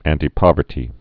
(ăntē-pŏvər-tē, ăntī-)